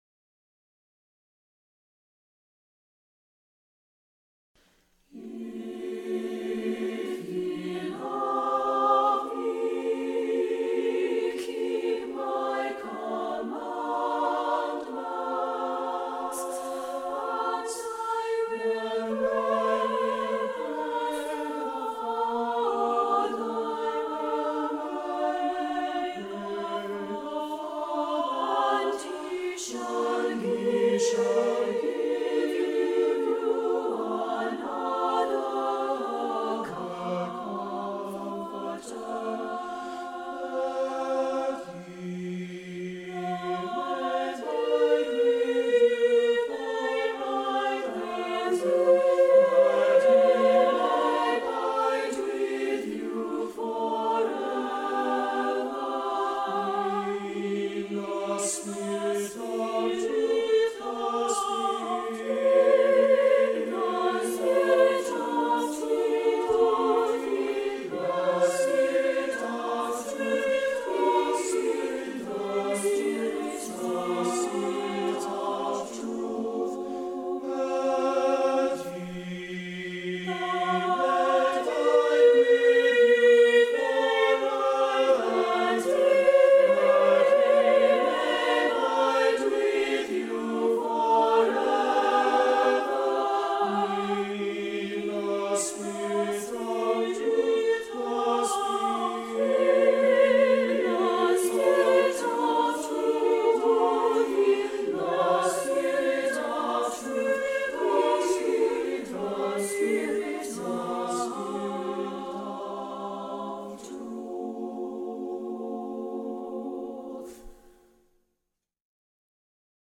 Accompaniment:      A Cappella
Music Category:      Early Music